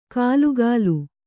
♪ kālugālu